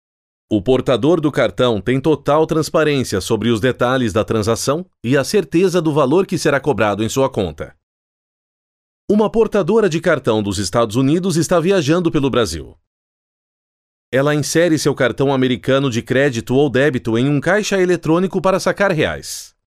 Voces profesionales brasileñas.
locutor brasileño